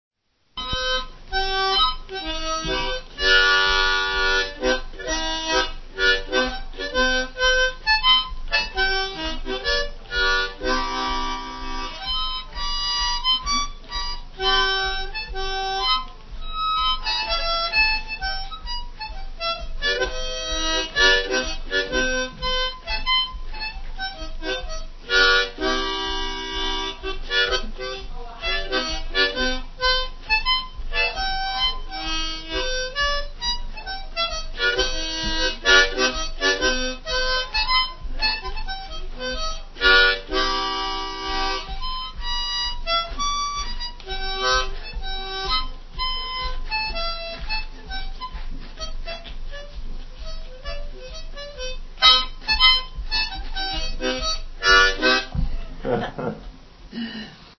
harmonica
Key: C
Meter: 4/4
Airs
Instrumentals--harmonica